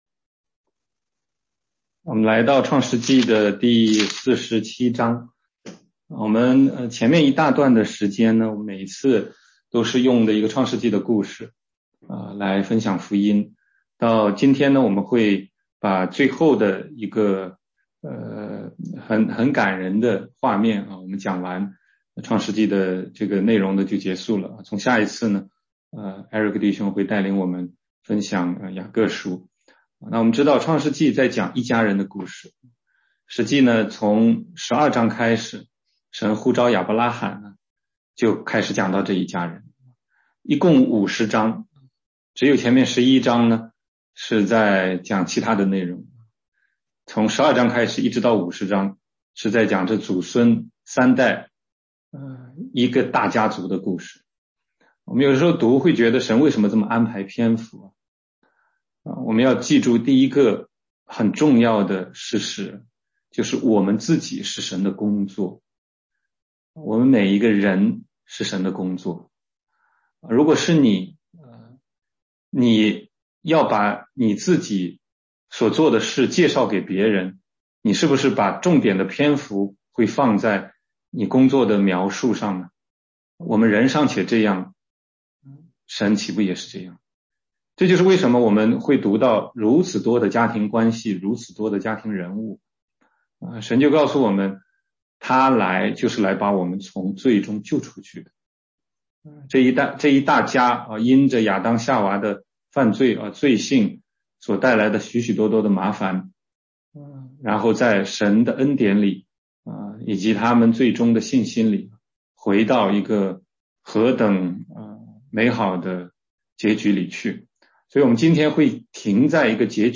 16街讲道录音 - 福音基础